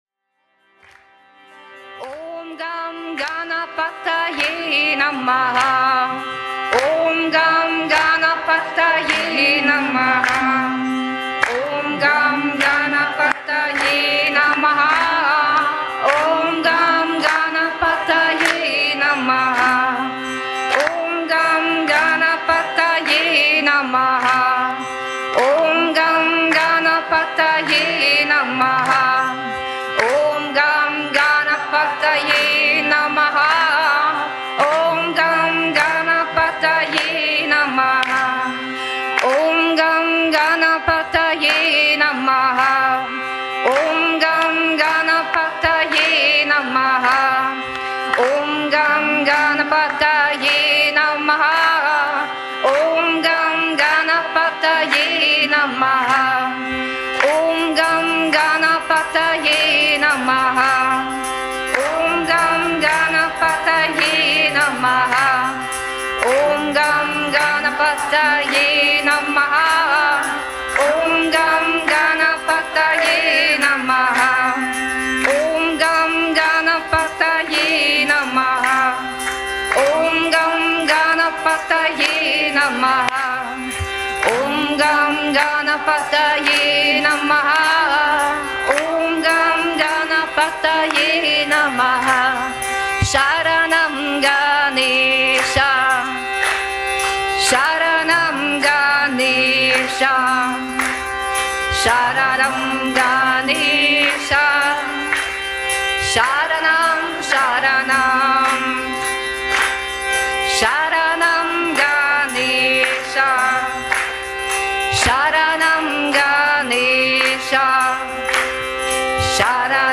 Om Gam Ganapataye Lausche dem Om Gam Ganapataye gesungen von neuen Yogalehrer:innen in einem Samstagabend Satsang bei Yoga Vidya in Bad Meinberg. Avahana Mantras sind Anrufungsmantras, um verschiedene Aspekte des Göttlichen einzuladen, da zu sein, erfahrbar zu sein.